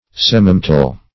Search Result for " semimetal" : The Collaborative International Dictionary of English v.0.48: Semimetal \Sem"i*met`al\, n. (Chem.) An element possessing metallic properties in an inferior degree and not malleable, as arsenic, antimony, bismuth, molybdenum, uranium, etc. [Obs.]